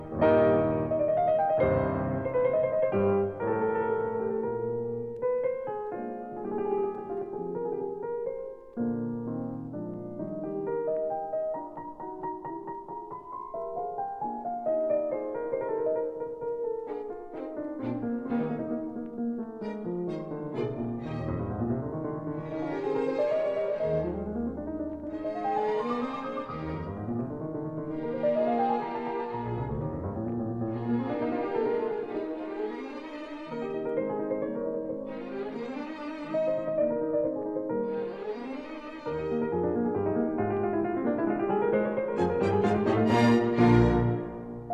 Allegro vivace